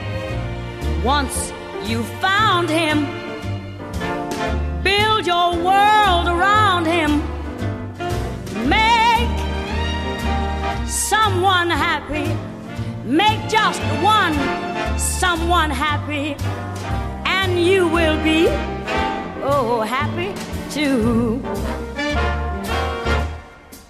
Here’s another sample sound that illustrates about every effect in one go. It is not very subtle - just to ensure everyone notices the difference to the original /uploads/default/original/2X/b/b4c3e57b17379ed09bb9476e483a1511e2b12dd0.mp3